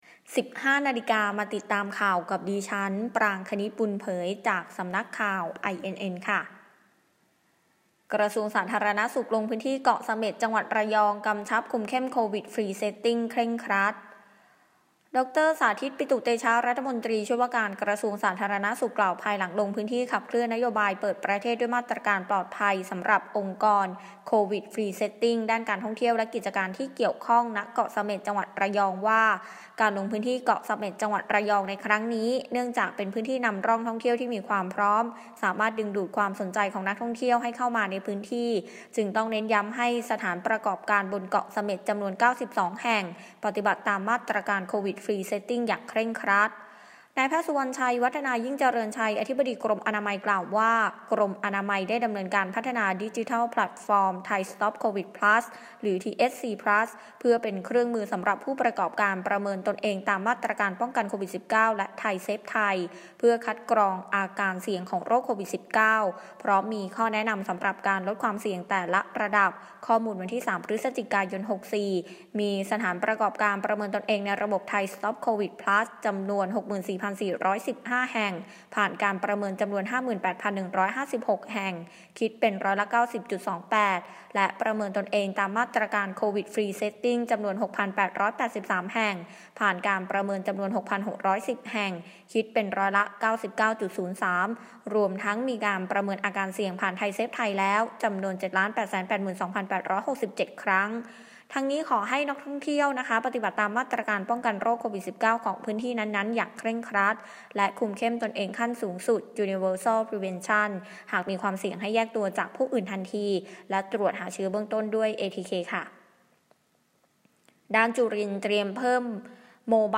ข่าวต้นชั่วโมง 15.00 น.